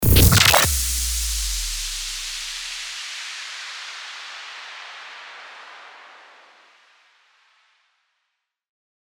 FX-1332-STARTER-WHOOSH
FX-1332-STARTER-WHOOSH.mp3